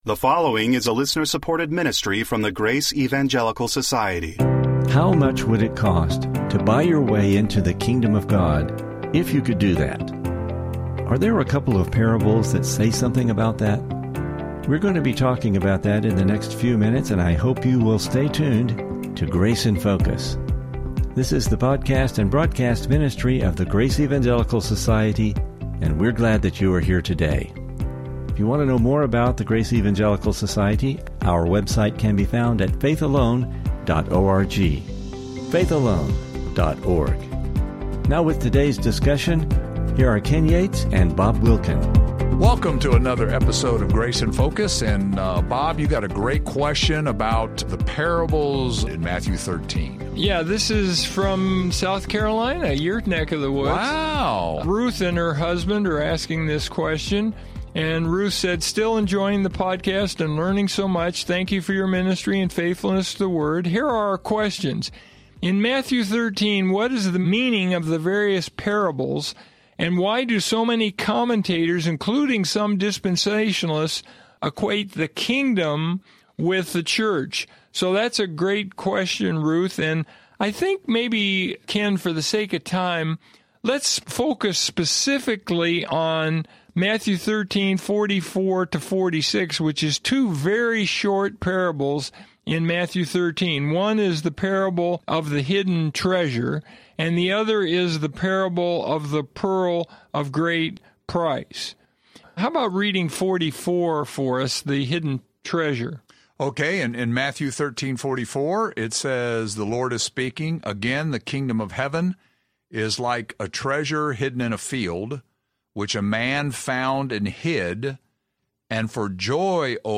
Welcome to Grace in Focus radio.